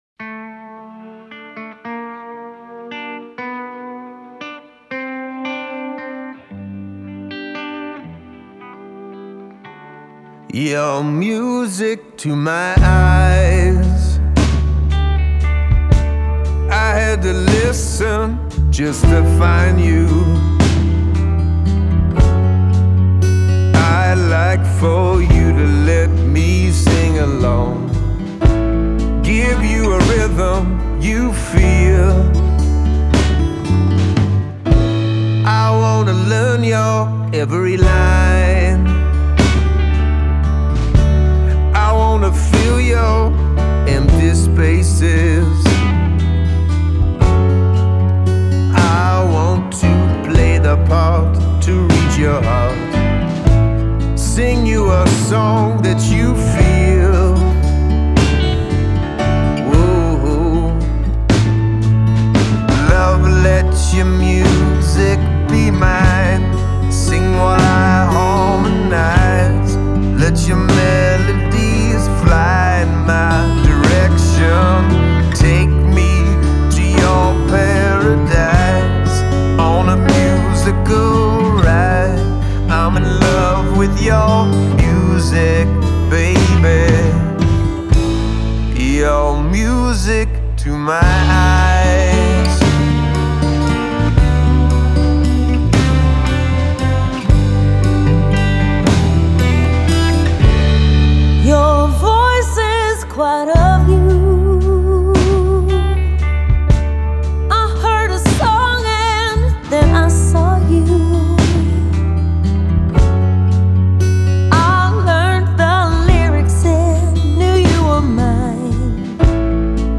We also posted another duet from the film .